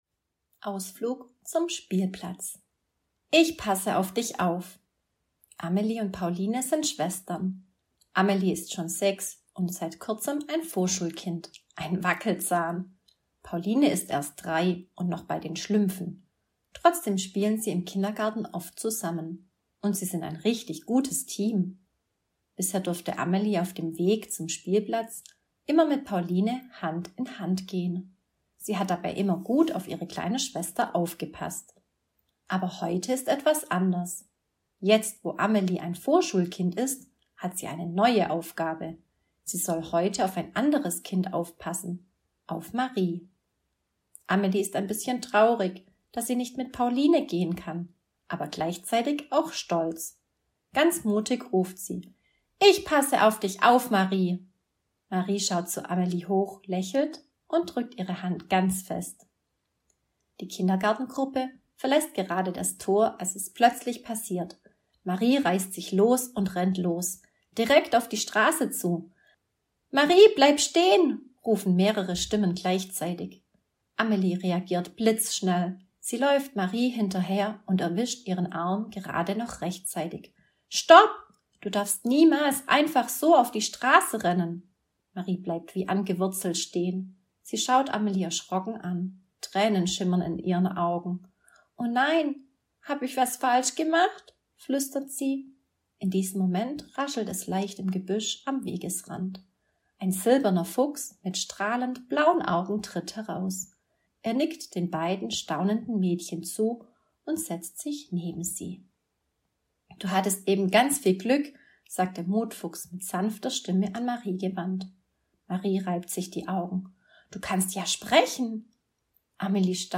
Diese Hörgeschichte aus meinem Buch „Der Mutfuchs – Dein Begleiter für die Vorschule“ ermutigt Vorschulkinder bei neuen Herausforderungen dranzubleiben.